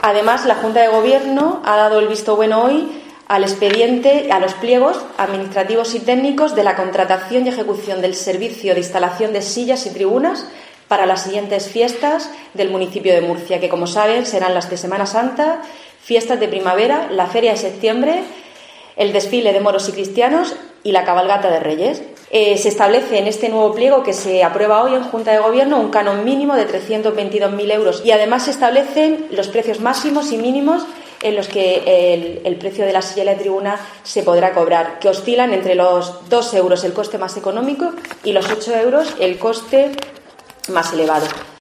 Paqui Pérez, portavoz del Ayuntamiento de Murcia